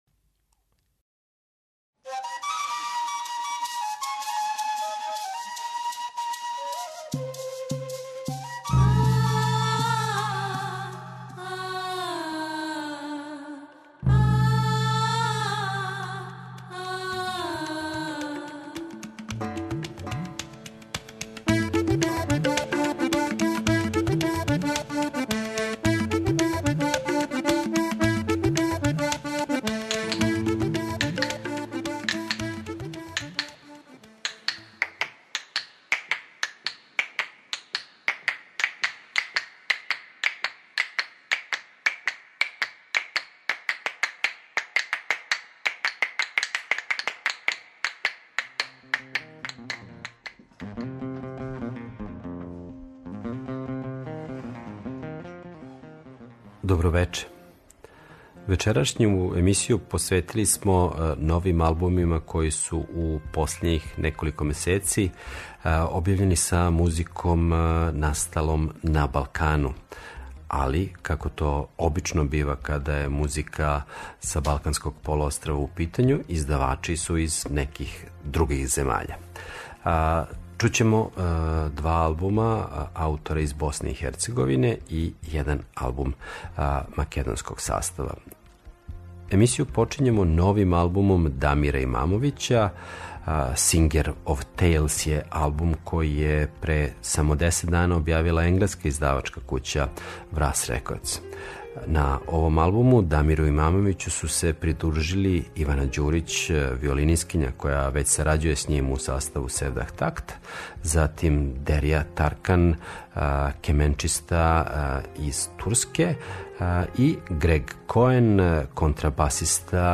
world music